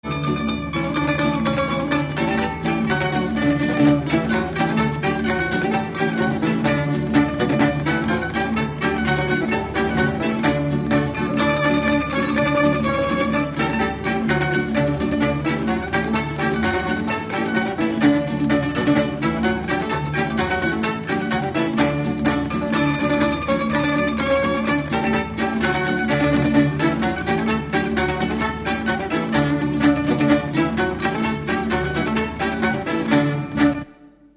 Dallampélda: Hangszeres felvétel
Alföld - Bács-Bodrog vm. - Dávod
tambura (prím)
klarinét (B)
tambura (basszprím)
tamburakontra
tamburabőgő
Műfaj: Ugrós
Stílus: 6. Duda-kanász mulattató stílus